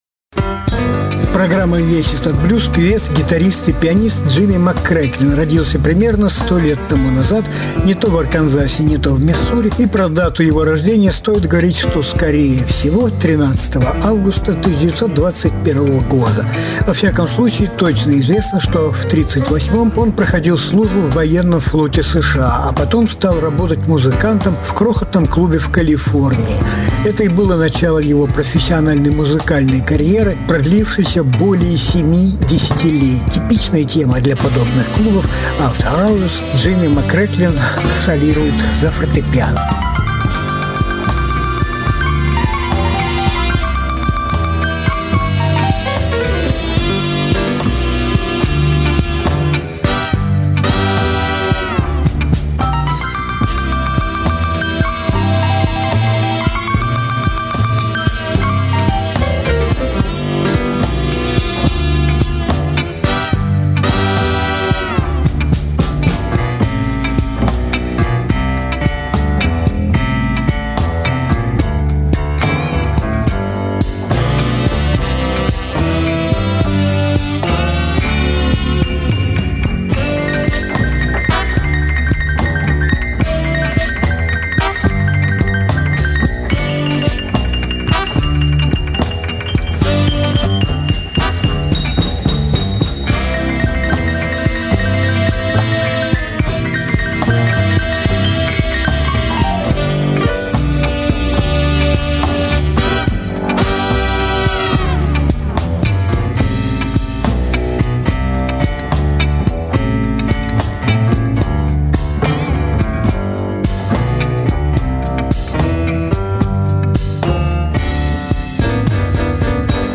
Жанр: Блюзы и блюзики